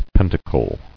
[pen·ta·cle]